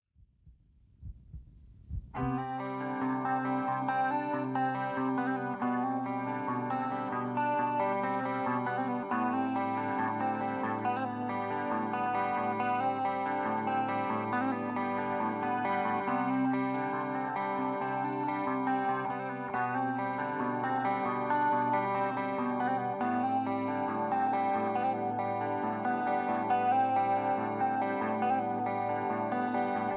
karaoke
rock